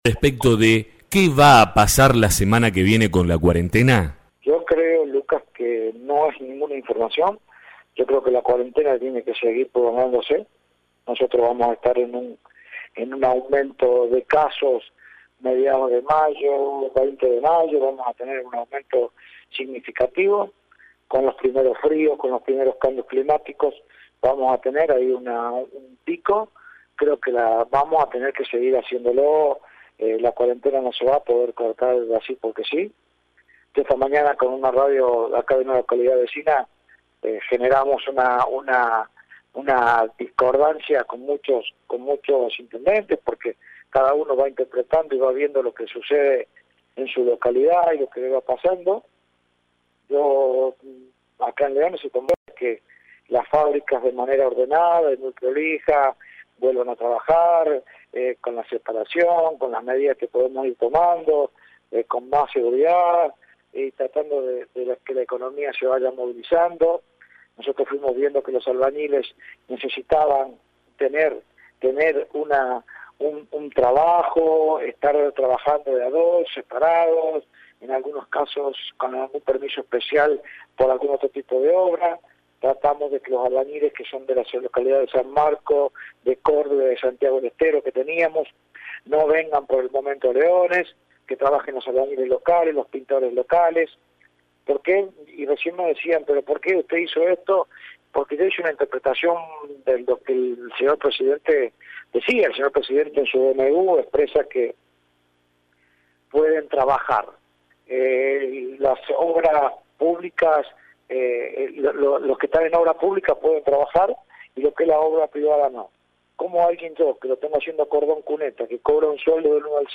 En diálogo con La Urbana, se refirió también al funcionamiento de las fábricas en Leones y argumentó las razones para habilitar parcialmente la actividad de la construcción.